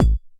描述：模拟模块化Kick鼓，在带有jomox modbase 09的eurorack上创建，运行到略微过载的制造噪音mmg滤波器，与噪音工程basimilus iteritas的打击乐噪音分层，然后被发送到温暖的音频wa76压缩器，这是一个1176克隆，大多数这些声音都采用了这些压缩器众所周知的“全进”比率技术。
标签： 压缩 模块化
声道立体声